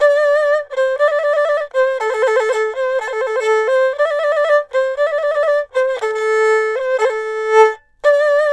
ERHU02120C.wav